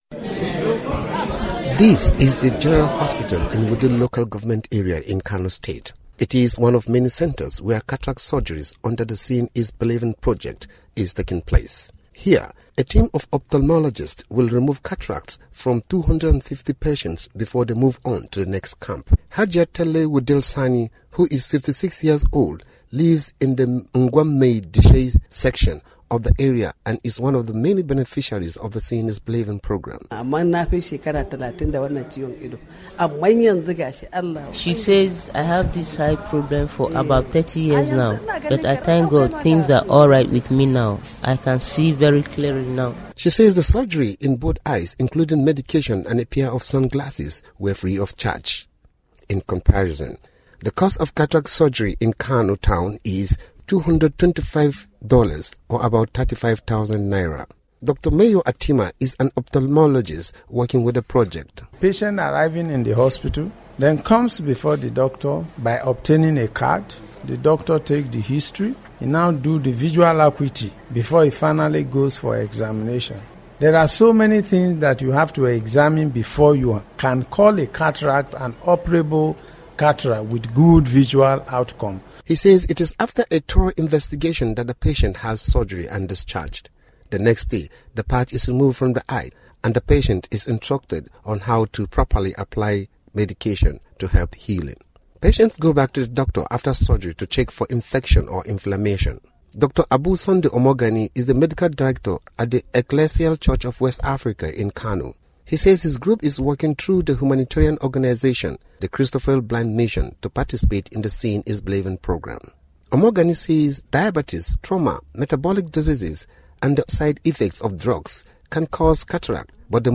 listen to report on eye care project in Kano, Nigeria